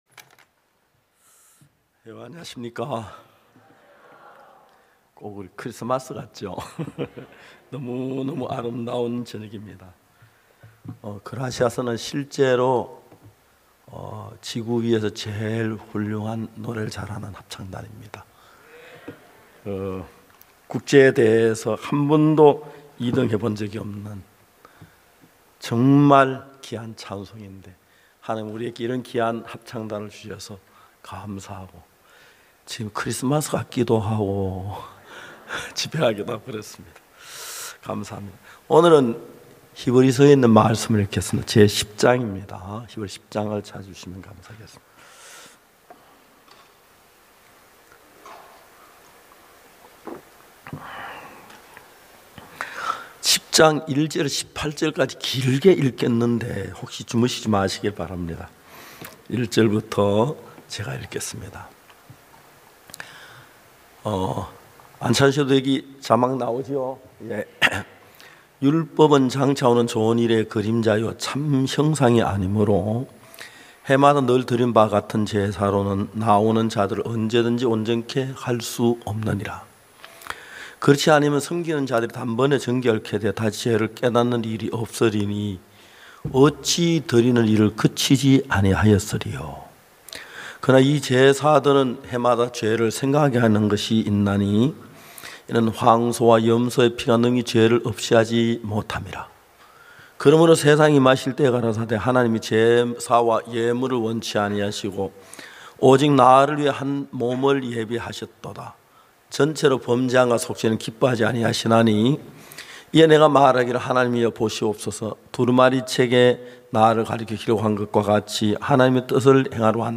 2022 후반기 부산 성경세미나